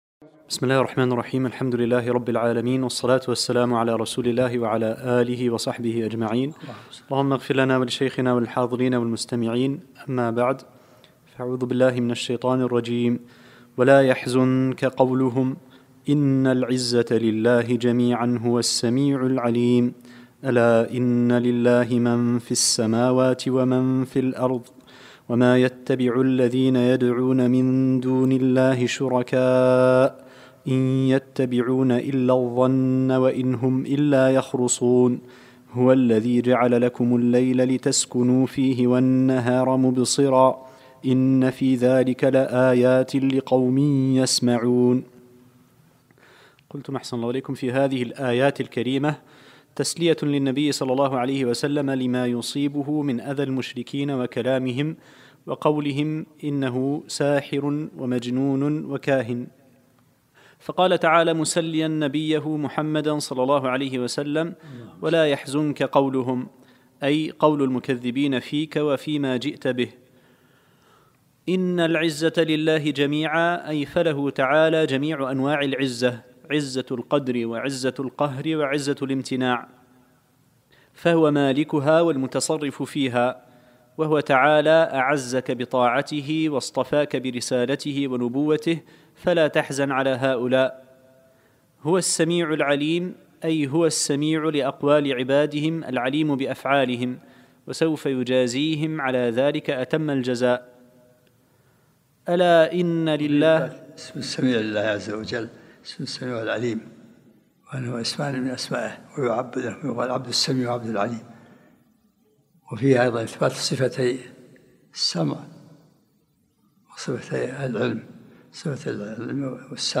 الدرس الخامس من سورة يونس